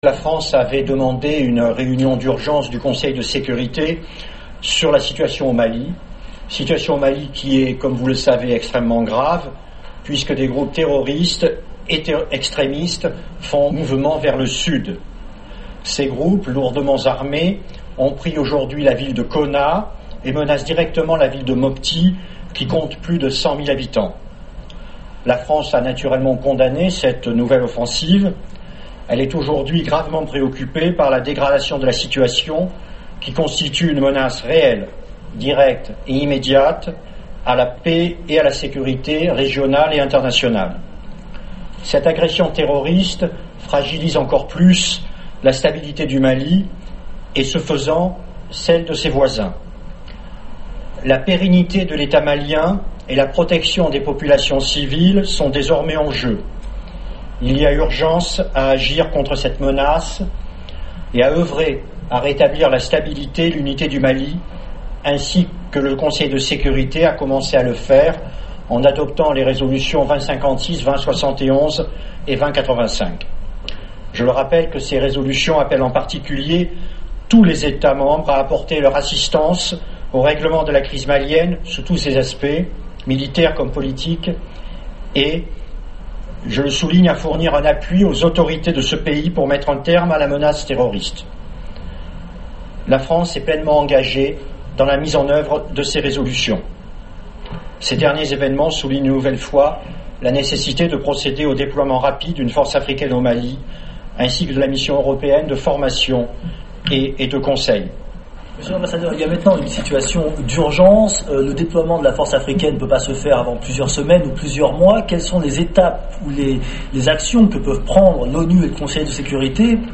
L'ambassadeur Gérard Araud a fait le point de la session du Conseil devant la presse